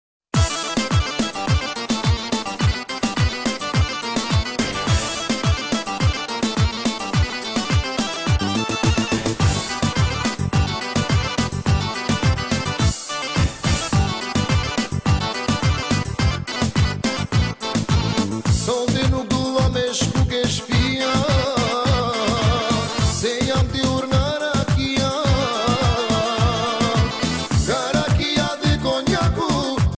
(p) 2014. 2 0 shop_two live_tv more_horiz In: Folk